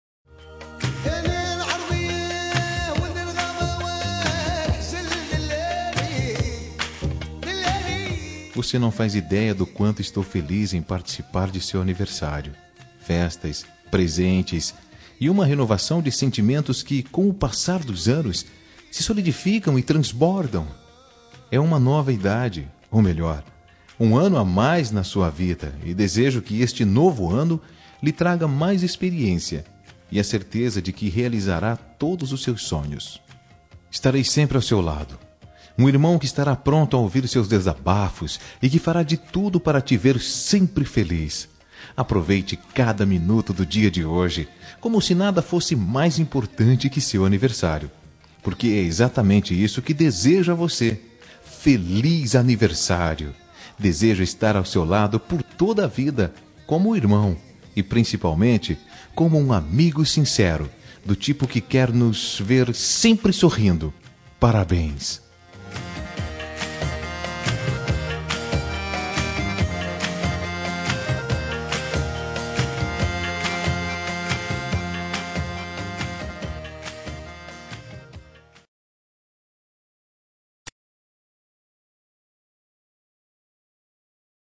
Telemensagem de Aniversário de Irmã – Voz Masculina – Cód: 4221